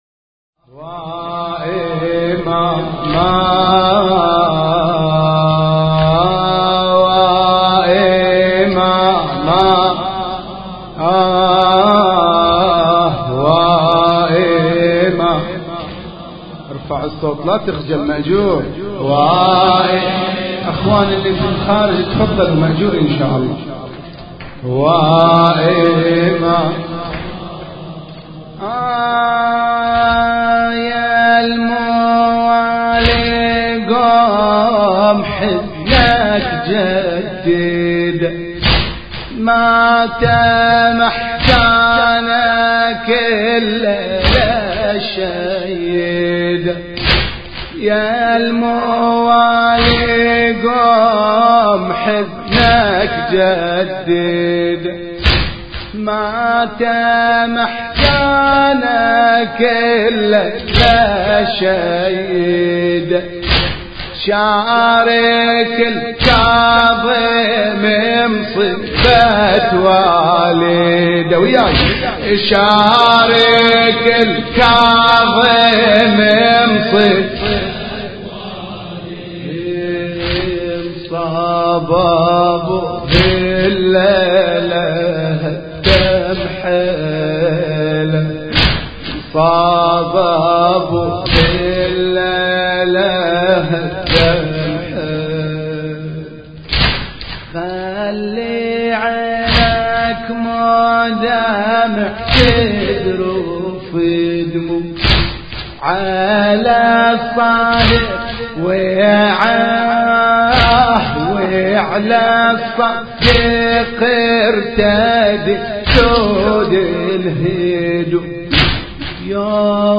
مراثي الامام الصادق (ع)